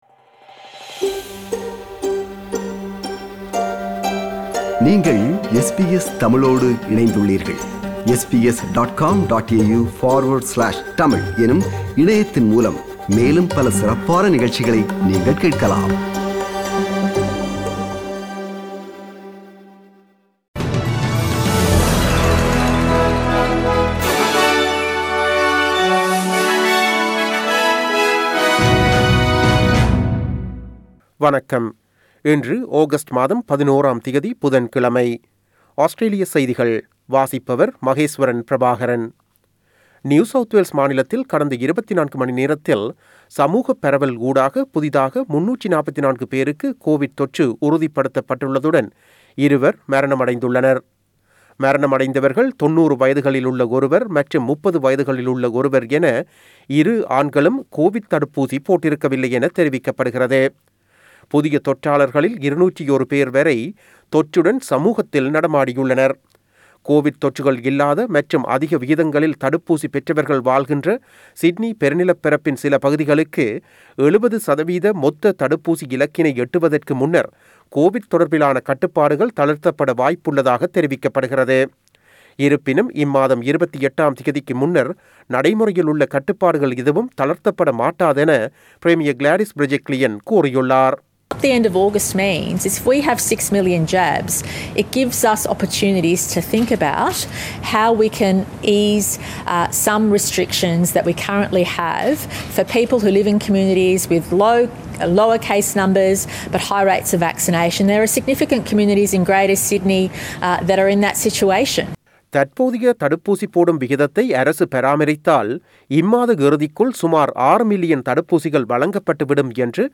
Australian news bulletin for Wednesday 11 August 2021.